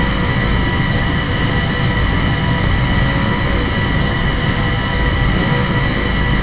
enginea-10.mp3